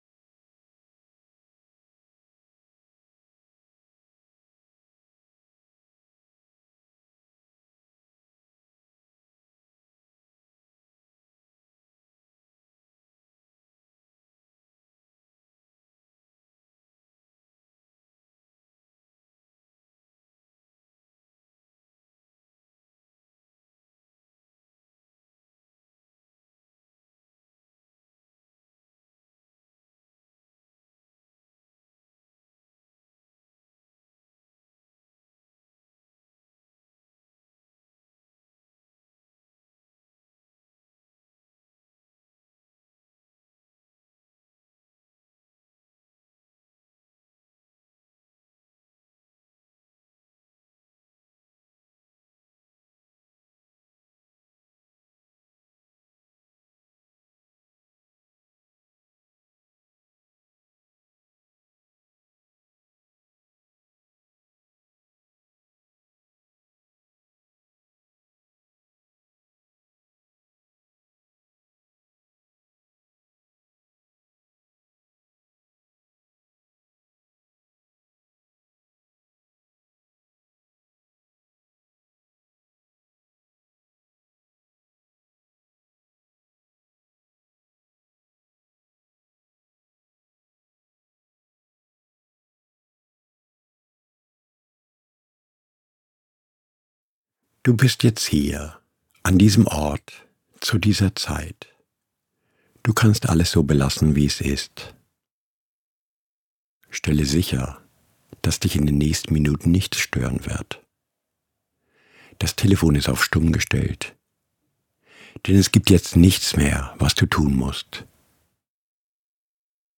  Blütenschlaf .. Nachtschlaferzählung Einschlaferzählungen sind für Abende, an denen man ahnt, dass das Einschlafen nicht leicht sein wird. Sie unterstützen eine positive Haltung zum Schlaf und zur Schlafenszeit, beschäftigen den Geist und besänftigen das Gedankenkarussell.
Zielgerichtete, therapeutisch wirksame, immersiv-meditative Erzählungen zum vielfachen Anhören. 20-40 Minuten Download mp3- Audio , binaural (mehr hierzu) .